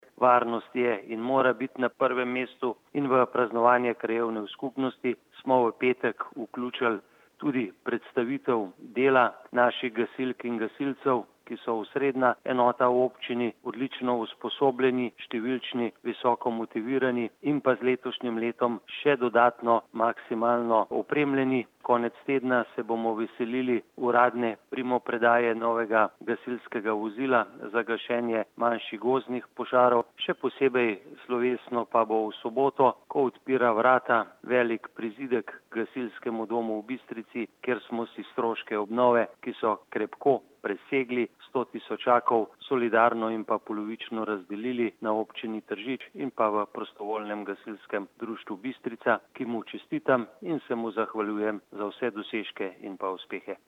izjava_zupanobcinetrzicmag.borutsajovicopraznovanjuksbistrica.mp3 (1,3MB)